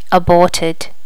Additional sounds, some clean up but still need to do click removal on the majority.
aborted.wav